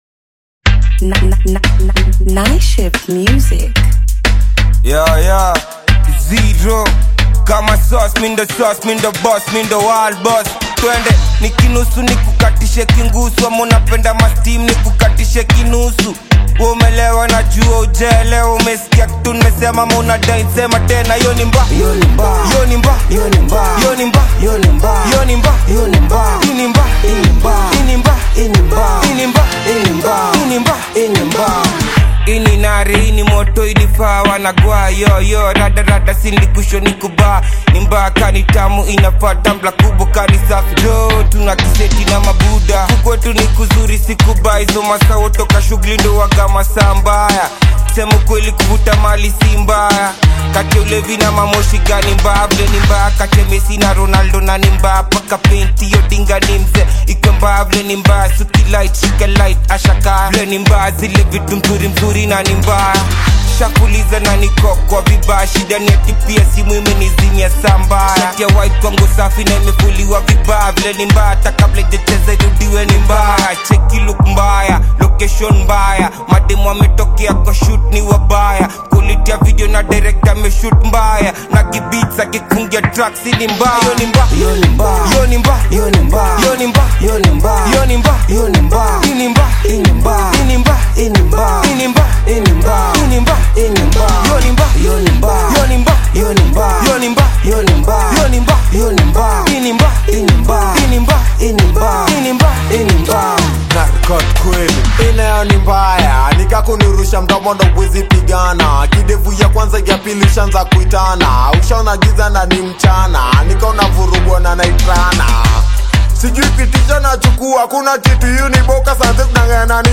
AudioHip HopKenyan Music
Bongo‑Flava/Gengetone single